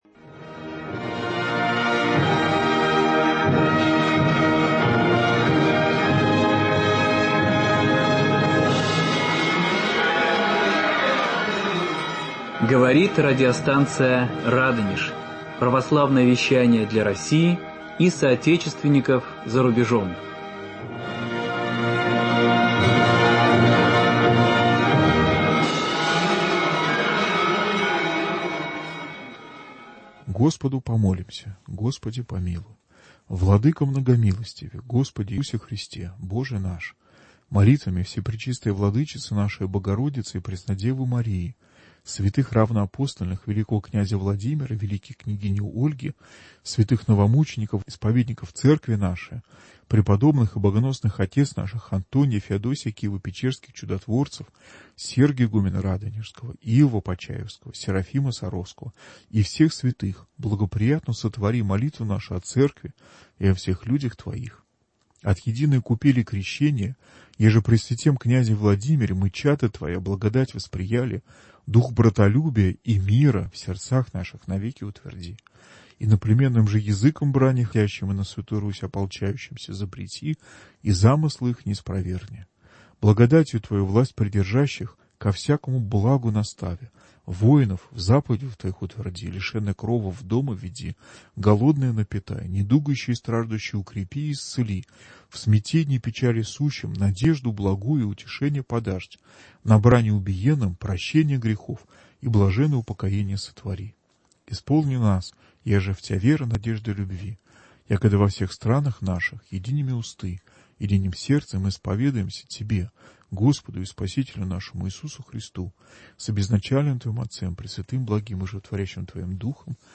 Должен ли художник много зарабатывать и может ли настоящий художник быть богатым? Обо всём этом и многом другом беседуем с лауреатом серебряной медали Российской Академии Художеств скульптором